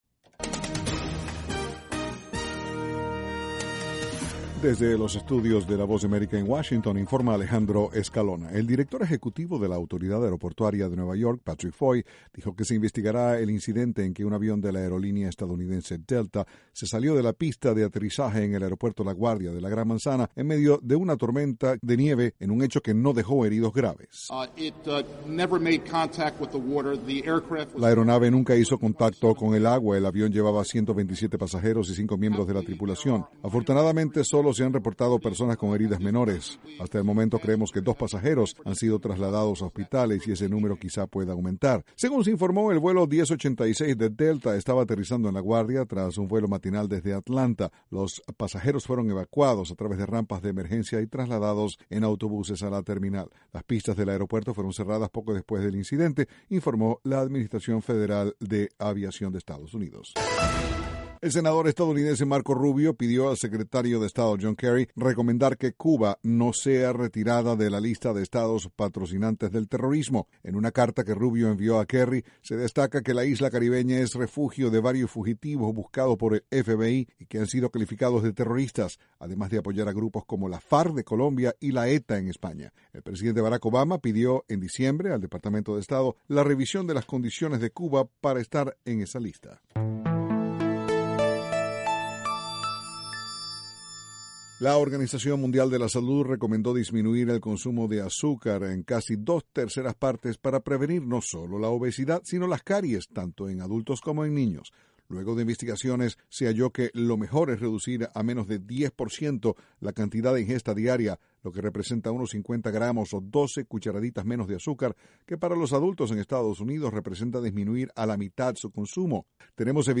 Noticias de la Voz de América